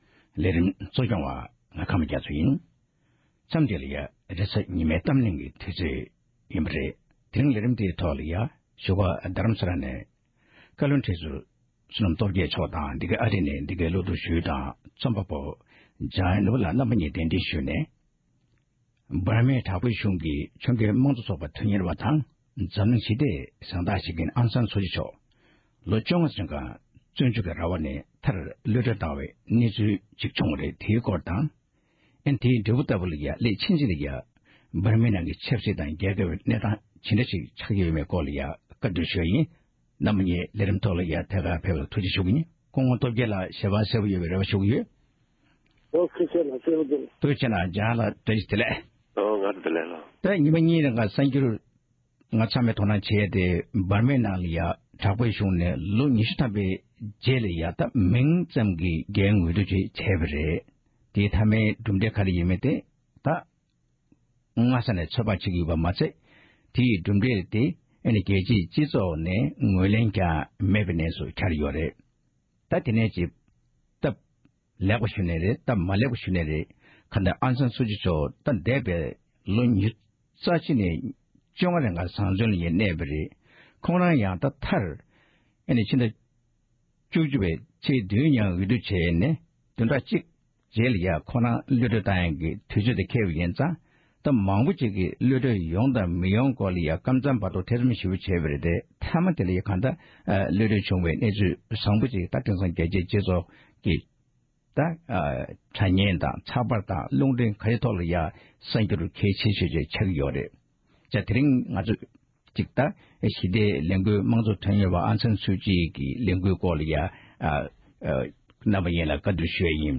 དེ་རིང་གི་རེས་གཟའ་ཉི་མའི་གཏམ་གླེང་གི་ལེ་ཚན་ནང